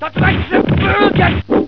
Tetsu-maki-swish&hit.wav